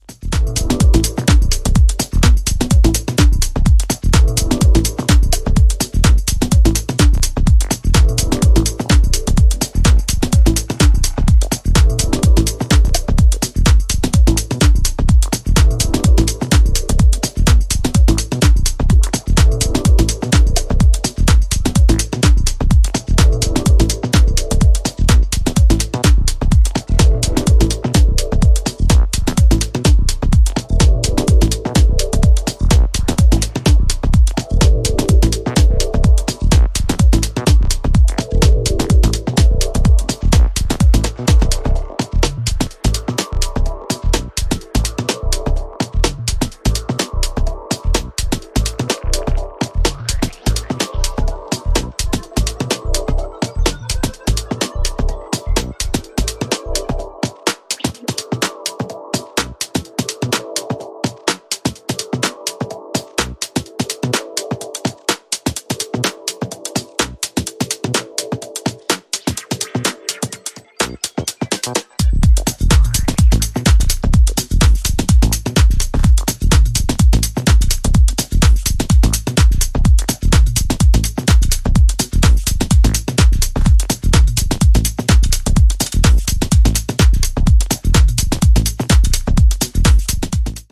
ジャンル(スタイル) MINIMAL / TECH HOUSE / DEEP HOUSE